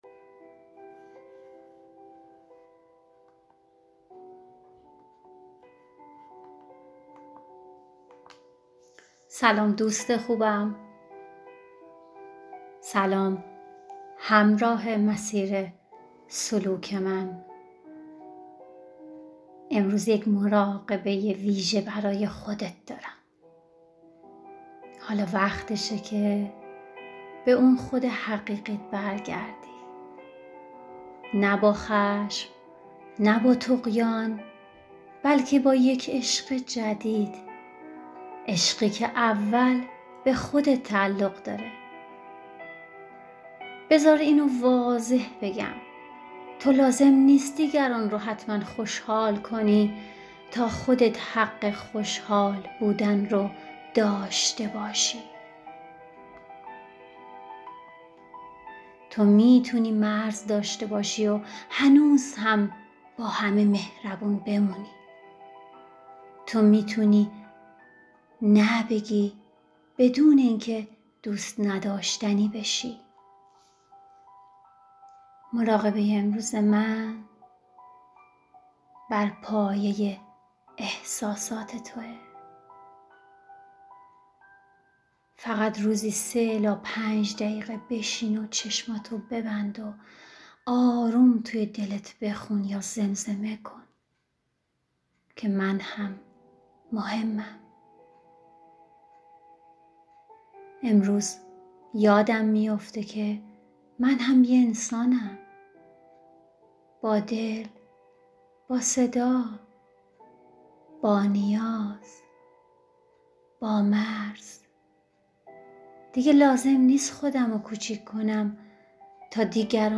مراقبه من هم مهمم
در این مراقبه‌ی آرام و عمیق، با خودت دیدار می‌کنی…